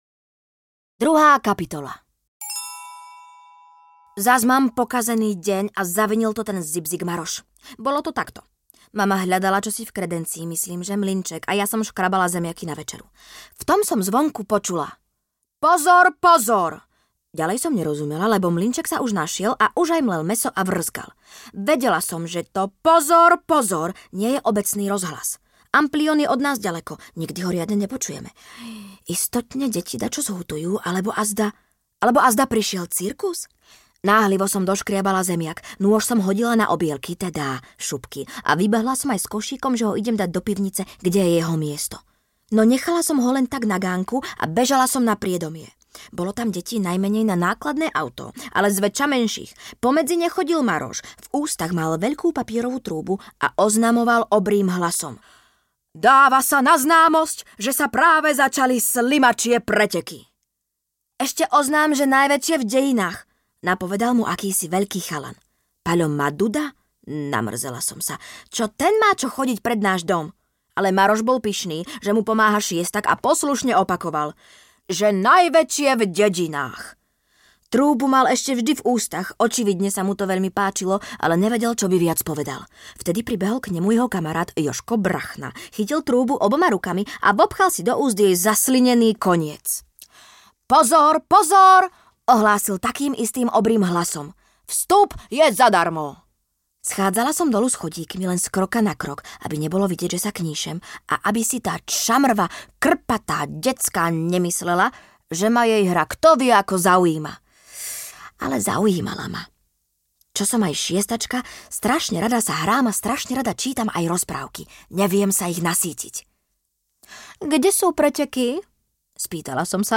Majka Tárajka audiokniha
Ukázka z knihy